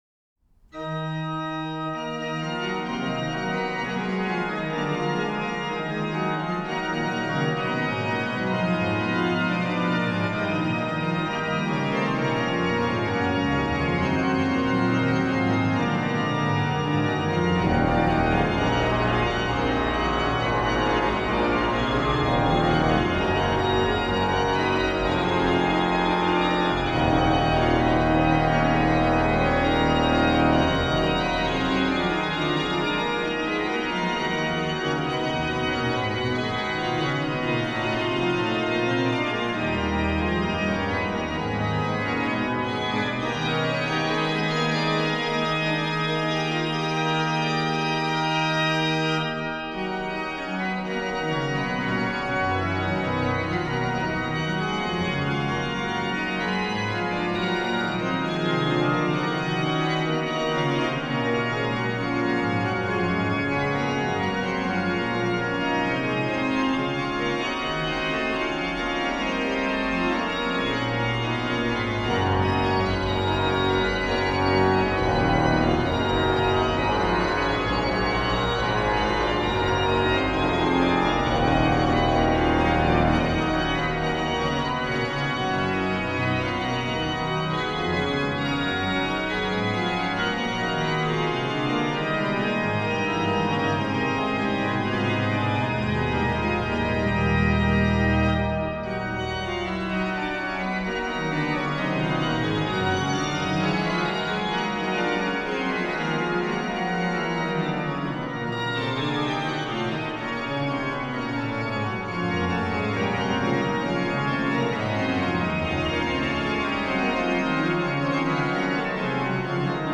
Subtitle   sub communione; pedaliter
Ped: HW/Ped; HW: Fgt16, Tr8